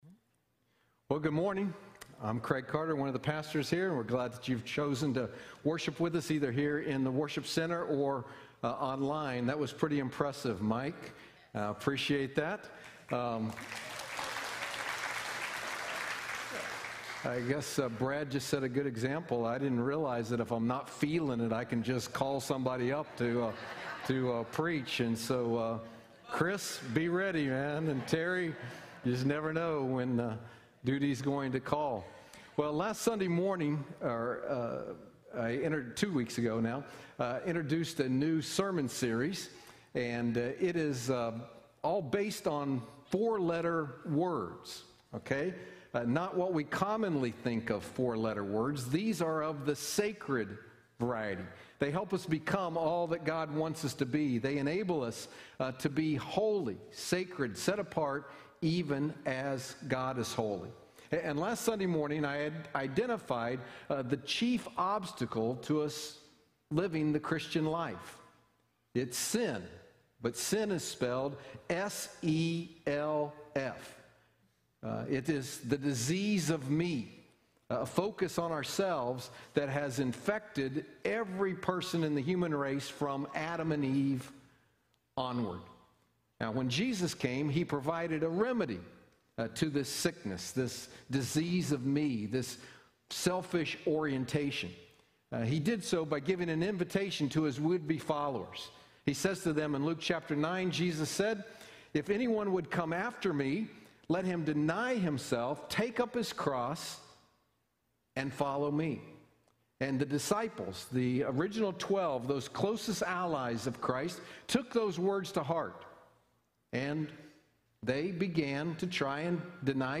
Sacred 4-Letter Words Service Type: Sunday Morning Download Files Notes Bulletin « Sacred 4-Letter Words
Sermon-Audio-1.24.21.mp3